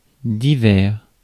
Ääntäminen
France (Paris): IPA: [di.vɛʁ]